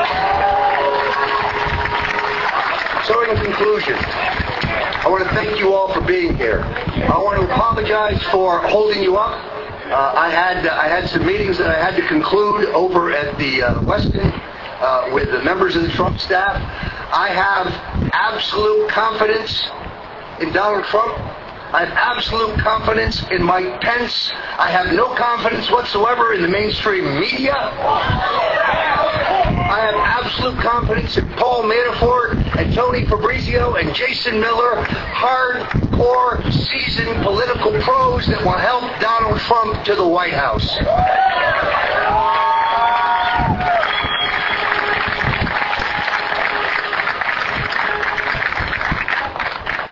These new details prompted me to reexamine a speech Stone gave during the Republican National Convention in July 2016, at a rally I was covering. In that speech, he referred to a meeting with Trump campaign staff.